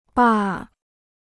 坝 (bà): dam; dike; embankment.